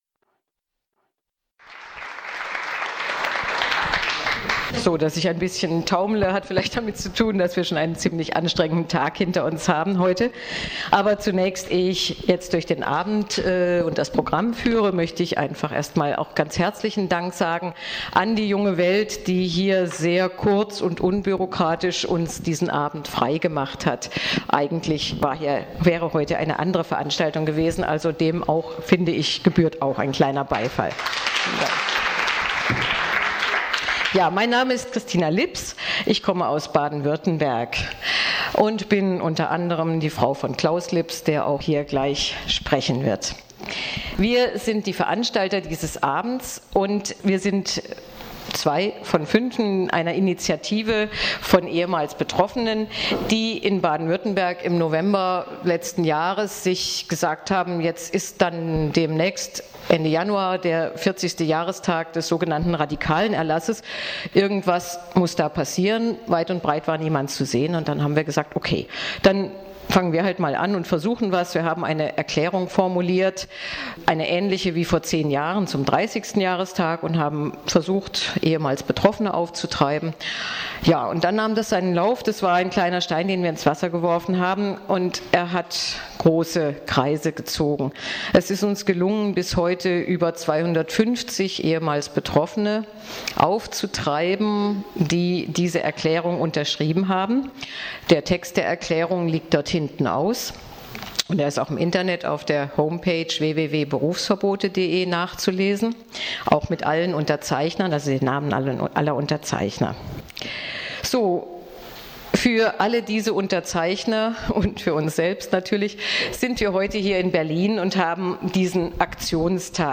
Polithistorische Revue mit 17 Zeitzeuginnen und Zeitzeugen aus 8 Bundesländern,
Begrüßung und Moderation